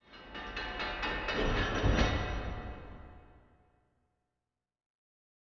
• 声道 立體聲 (2ch)